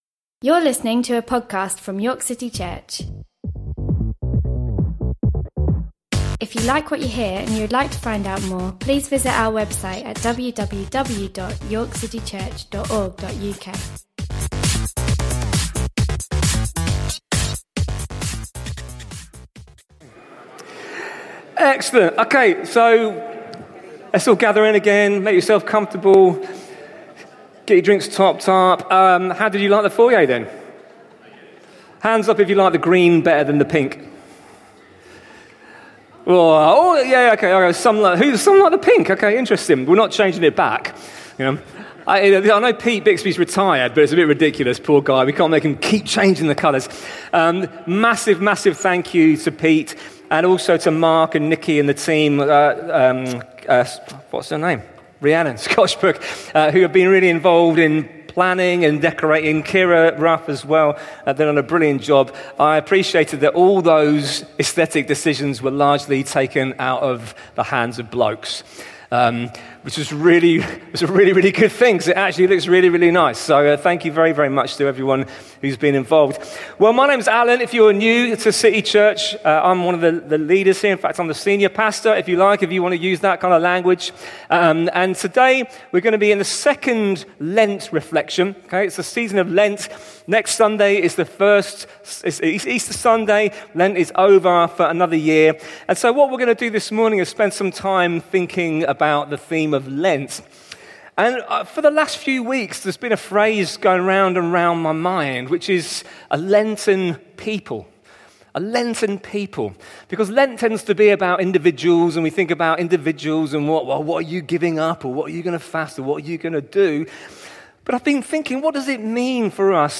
A one-off message, often by a visiting speaker.